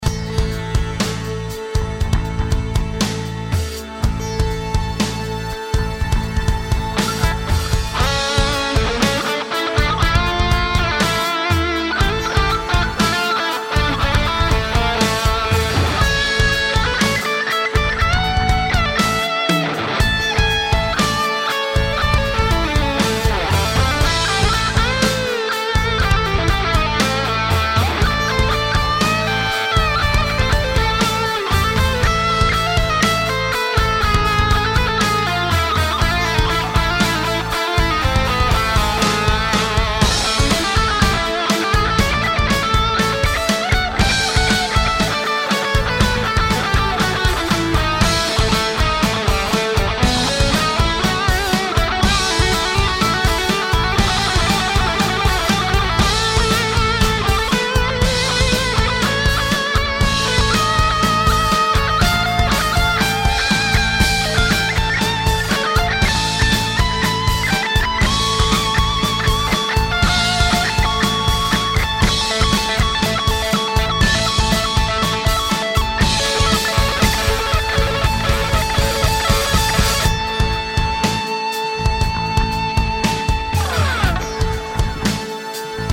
To demonstrate, here's a jam I wrote using only the note A. Atop it, I cycle through several different scales.
• A Phrygian Dominant
• A Hungarian Minor
Guitar Solo Over A Drone - Multiple Scales
guitar-solo-many-scales.mp3